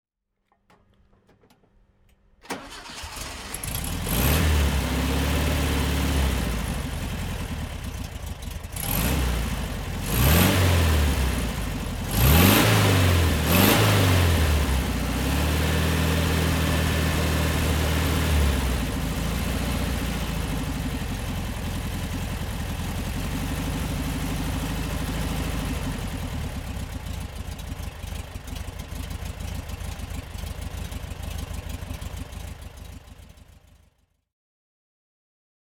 To give you an impression of the variety, we have selected ten different engine sounds.